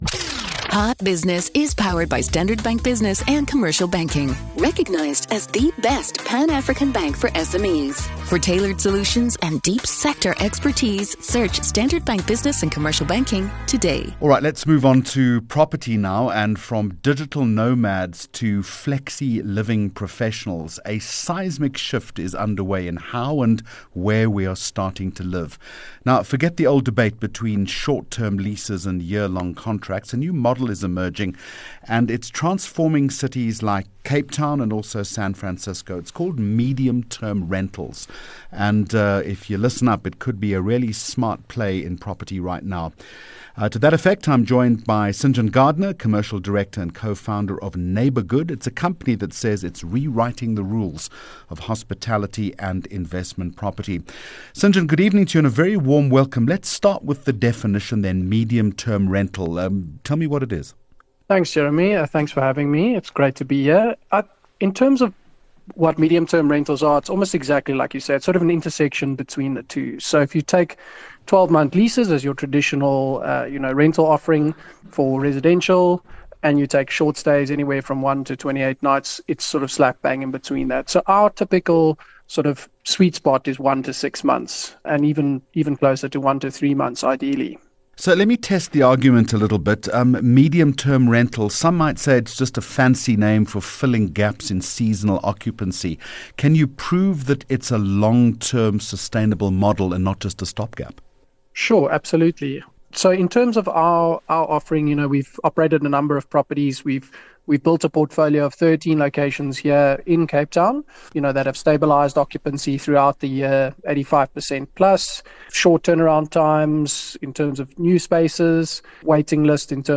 24 Jun Hot Business Interview